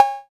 35 808 BELL.wav